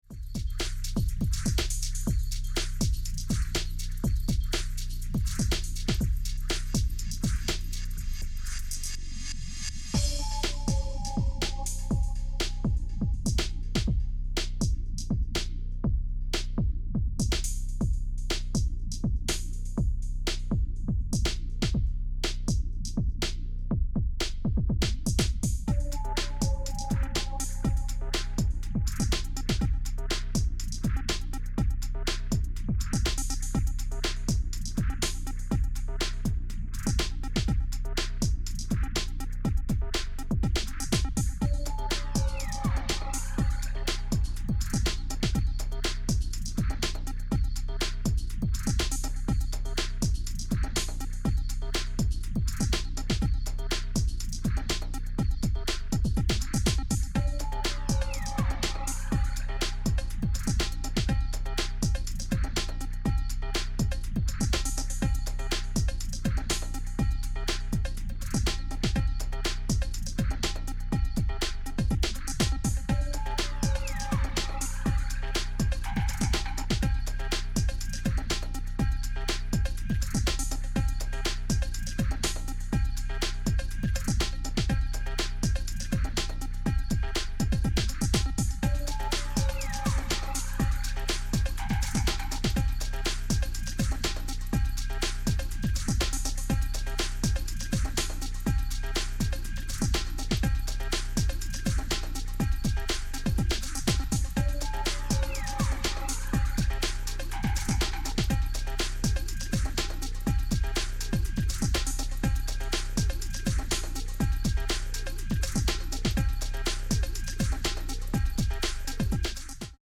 as moody synth work rides a subdued breakbeat.
Electro , House